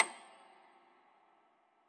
53k-pno30-C7.wav